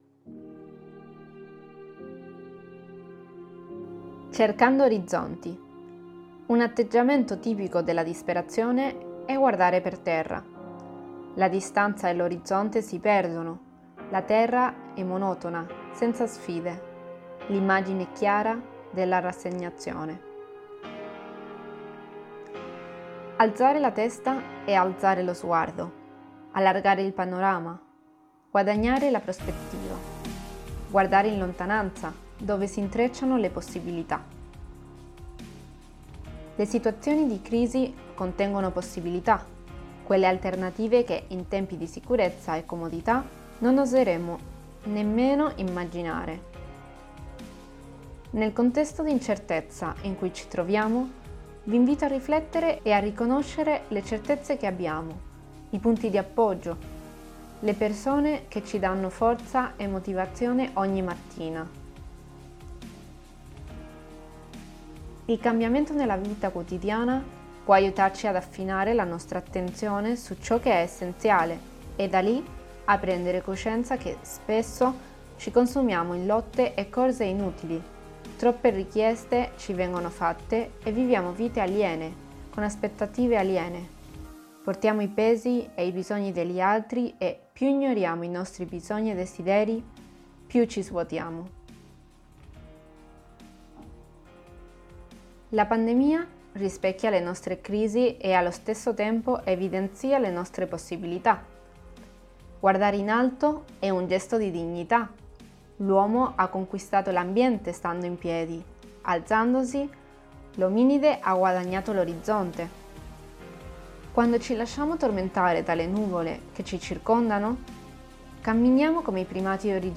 Italia > Audioletture